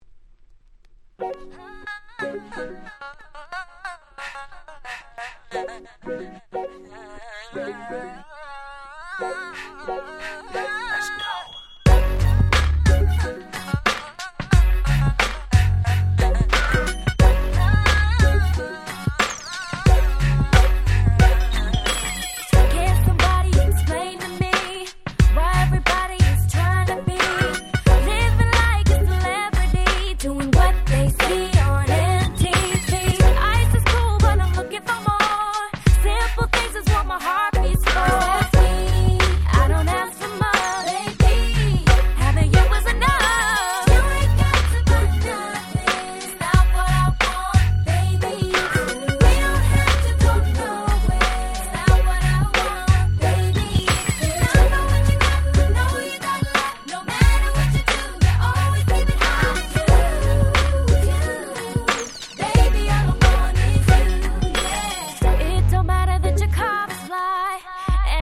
※一部試聴ファイルは別の盤から録音してございます。
04' Smash Hit R&B !!
可愛いVocalが堪りません！